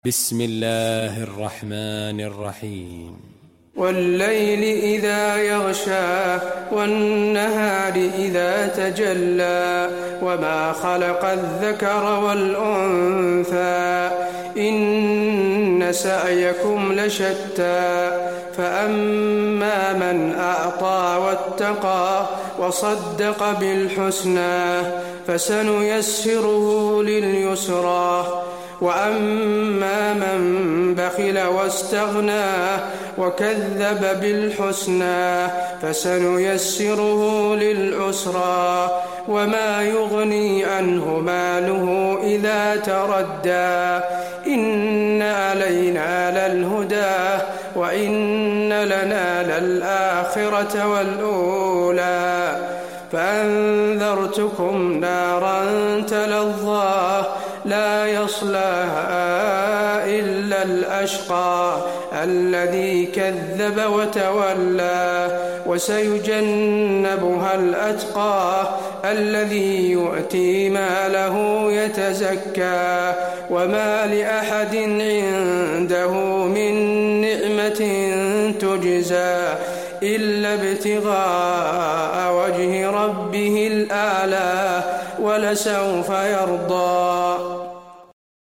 المكان: المسجد النبوي الليل